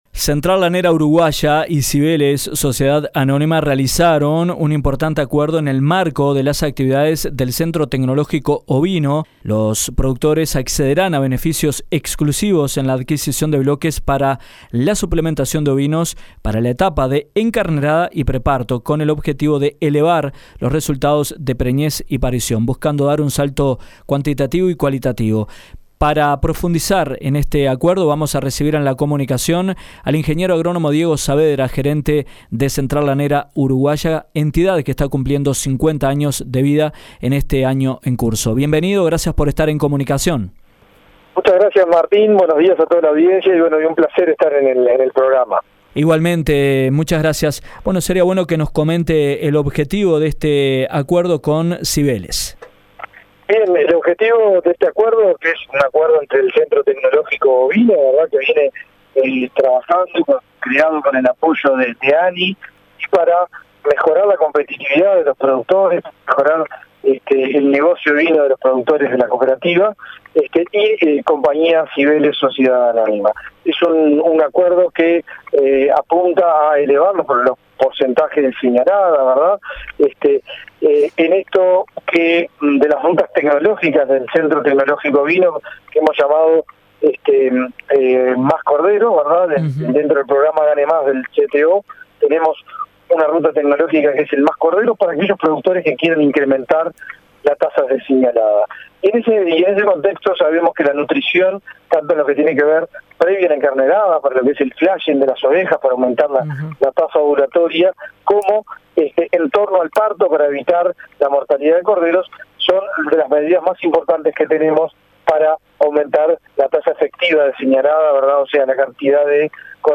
Central Lanera Uruguaya y Compañía Cibeles realizaron un importante acuerdo en el marco de las actividades del Centro Tecnológico Ovino (CTO) de CLU. Los productores accederán a beneficios exclusivos en la adquisición de bloques para la suplementación de ovinos, para la etapa de encarnerada y pre-parto, con el objetivo de elevar los resultados de preñez y parición. En entrevista